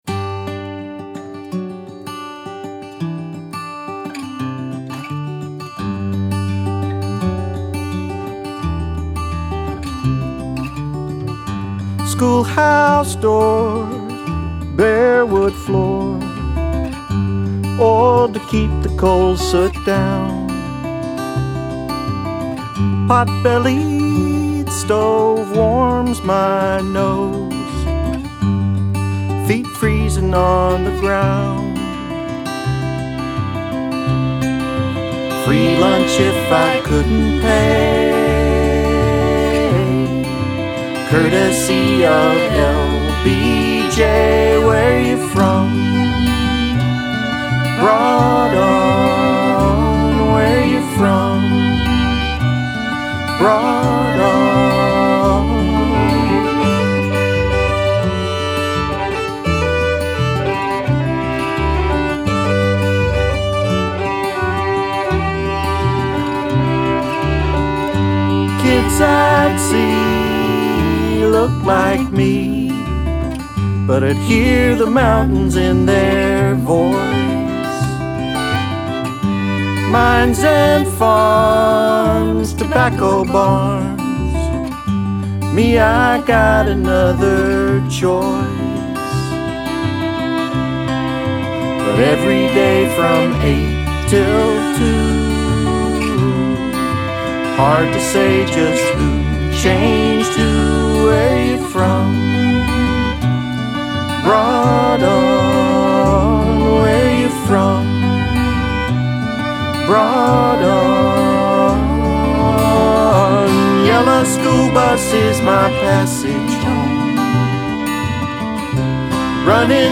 Appalachian, harmony